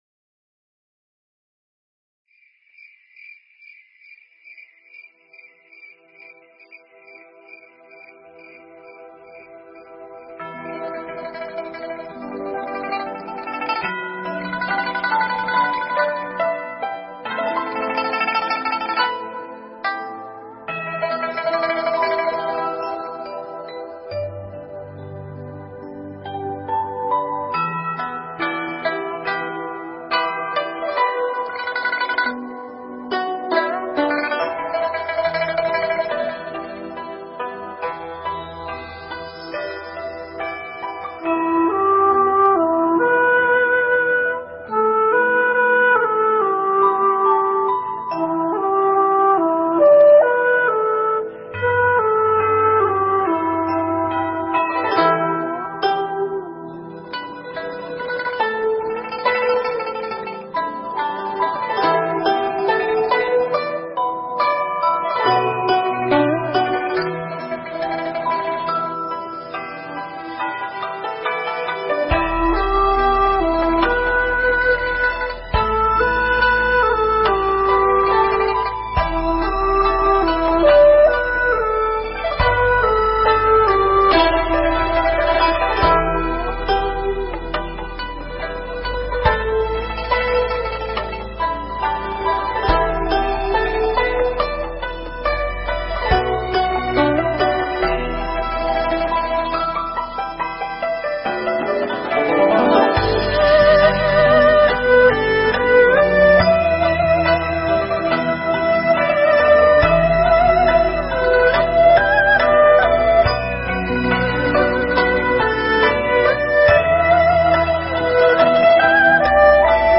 thuyết giảng tại Tu Viện Trúc Lâm, Canada